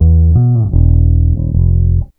BASS 35.wav